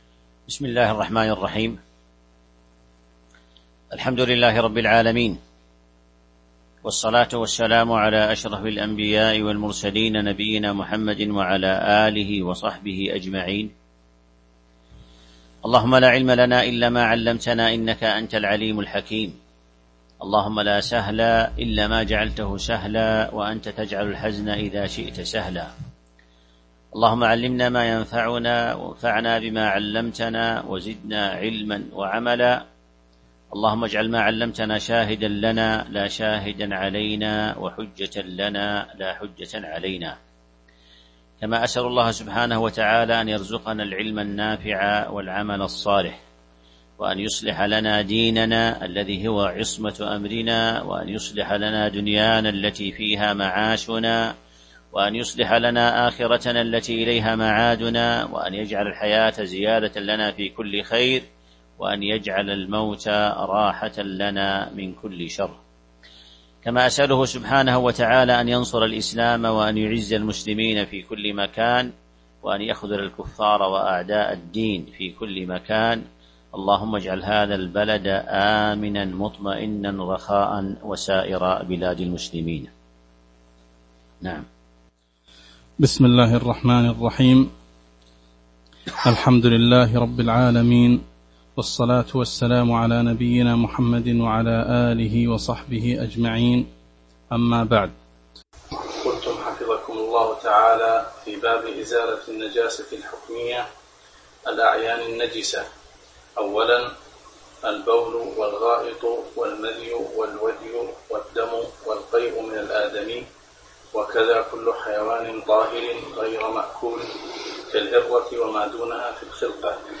تاريخ النشر ١٧ شوال ١٤٤١ هـ المكان: المسجد النبوي الشيخ